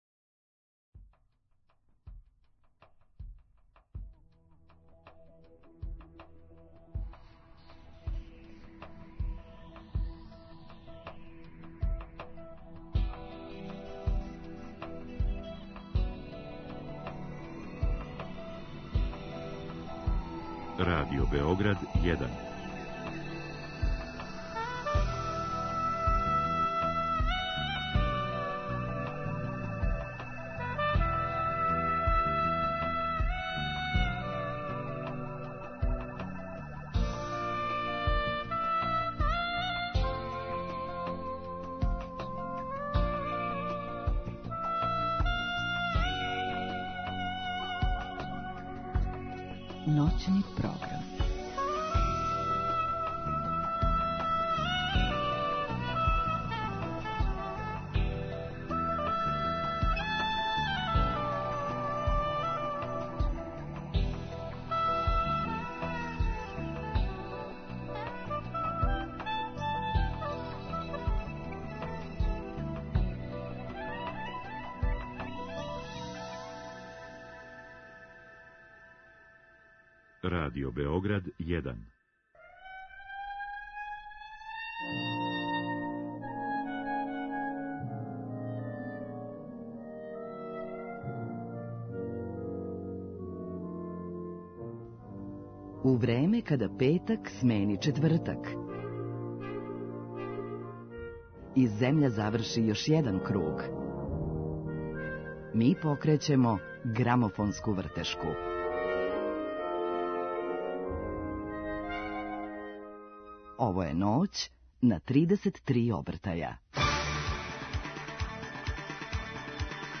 композитор, текстописац музички продуцент и мулти инструменталиста